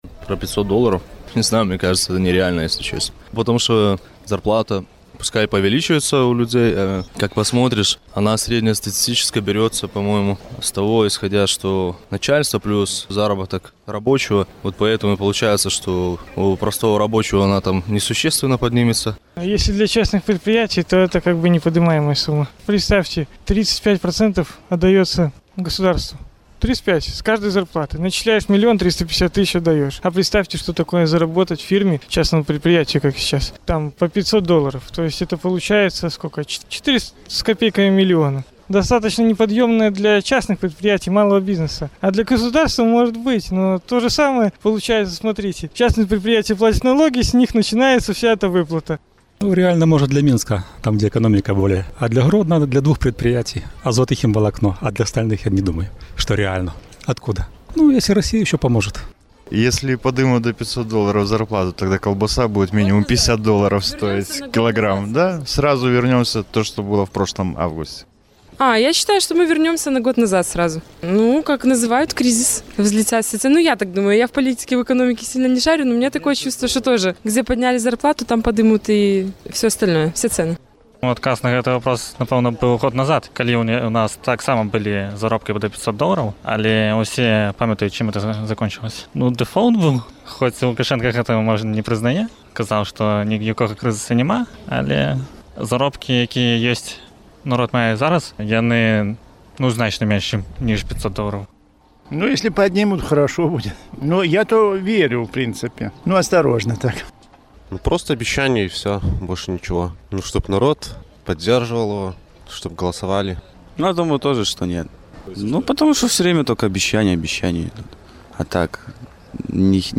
Ці верыце вы, што будзе заробоак 500 даляраў да канца году? Адказваюць гарадзенцы